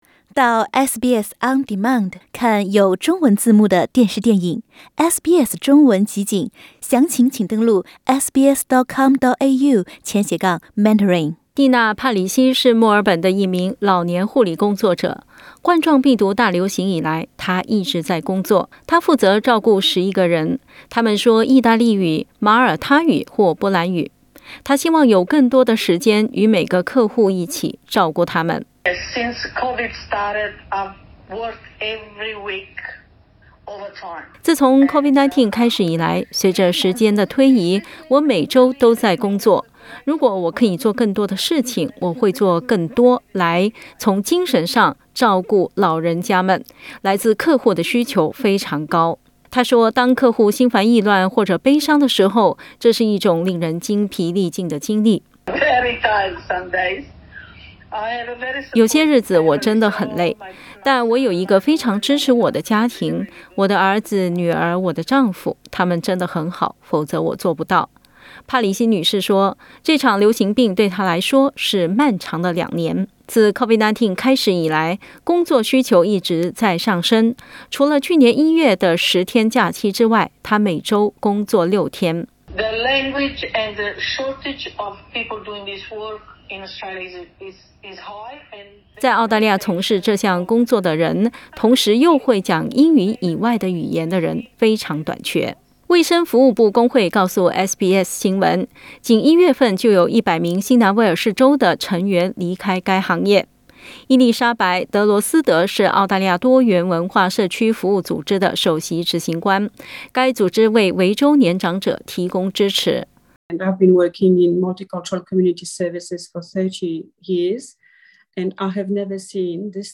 由于大流行和边境管制，进入澳大利亚的移民数量减少，导致老年护理行业劳动力出现缺口。(点击图片收听报道）